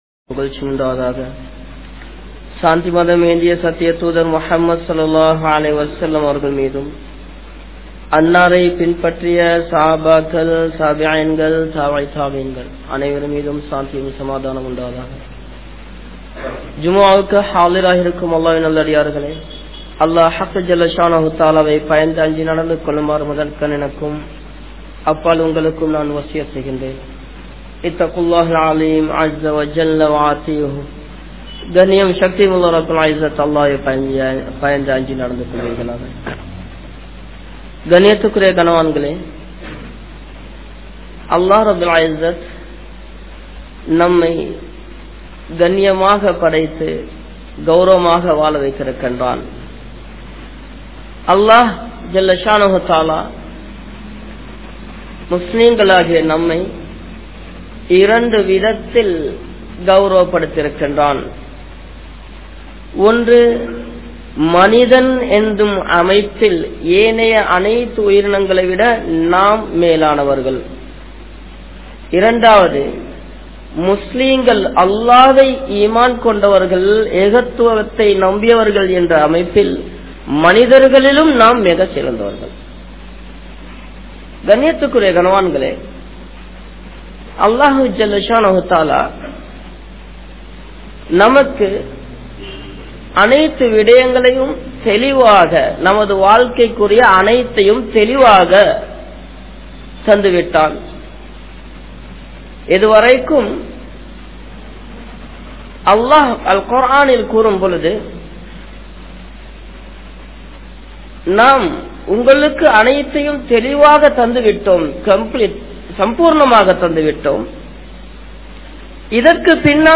Islaathil Dhaady Valarpathan Avasiyam Ean? (இஸ்லாத்தில் தாடி வளர்ப்பதன் அவசியம் ஏன்?) | Audio Bayans | All Ceylon Muslim Youth Community | Addalaichenai
Manar Jumua Masjith